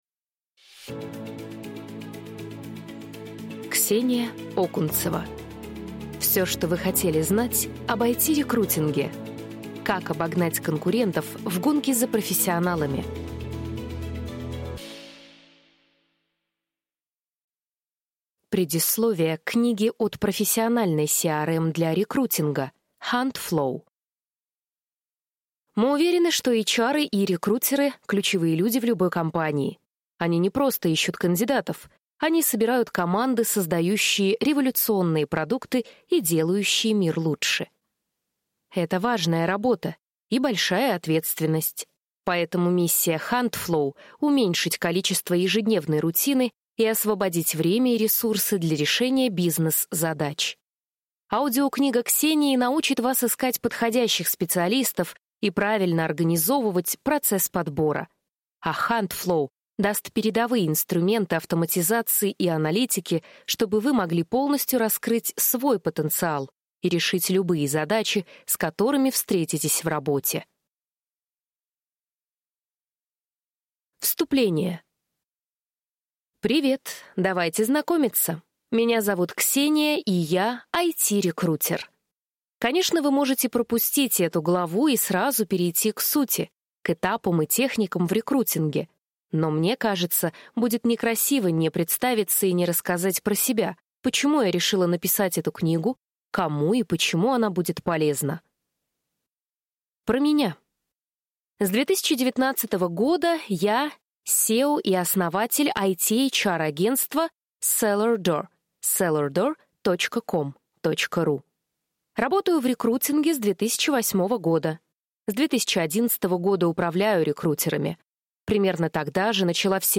Аудиокнига Все, что вы хотели знать об IT-рекрутинге. Как обогнать конкурентов в гонке за профессионалами | Библиотека аудиокниг